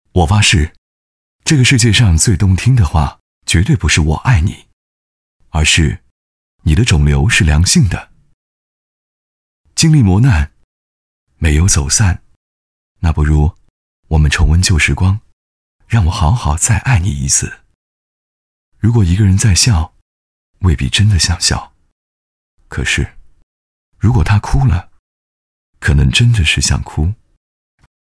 茉莉花旁白配音男355号